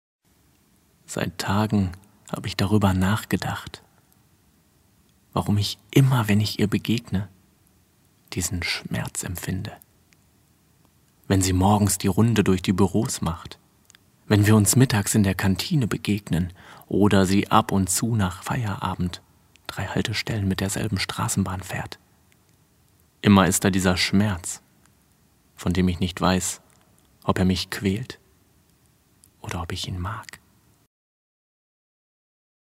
Profi-Sprecher und Schauspieler mit wandelbarer Stimme von seriös bis böse
Sprechprobe: Werbung (Muttersprache):